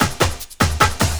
50LOOP03SD-L.wav